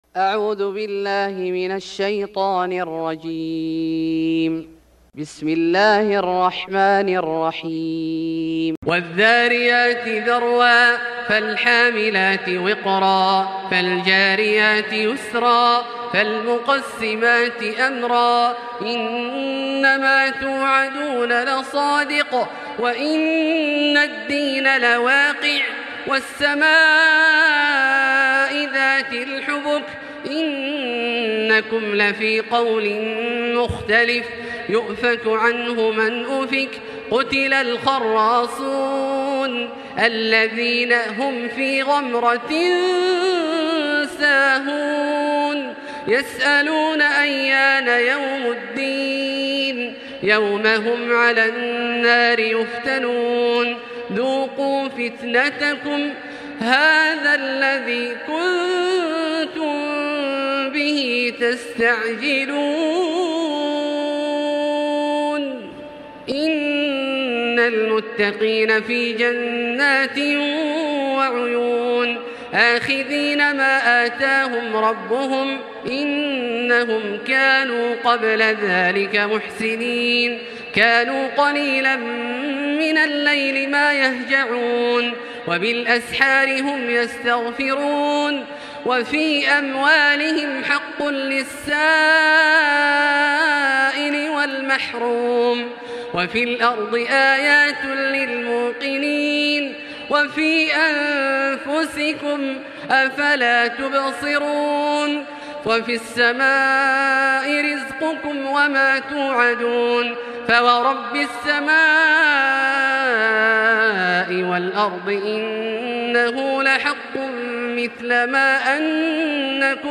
سورة الذاريات Surat Al-Dharyat > مصحف الشيخ عبدالله الجهني من الحرم المكي > المصحف - تلاوات الحرمين